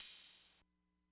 Metro Openhats [Trap Ophat].wav